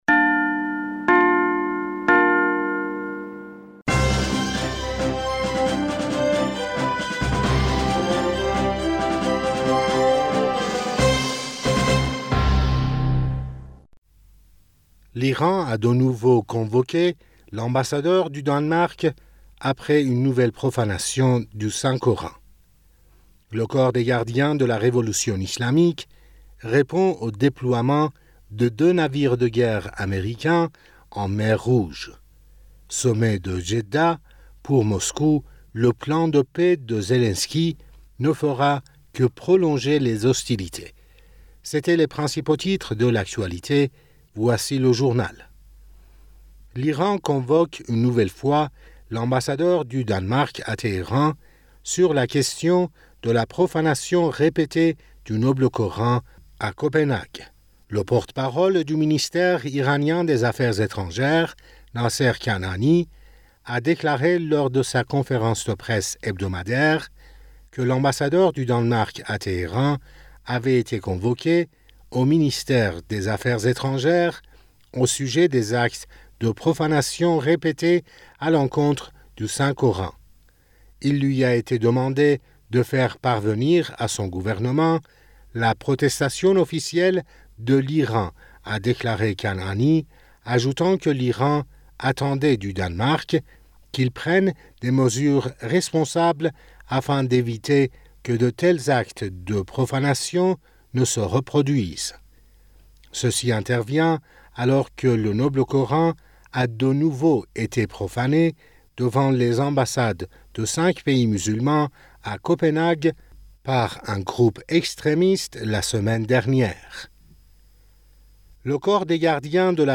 Bulletin d'information du 08 Aout 2023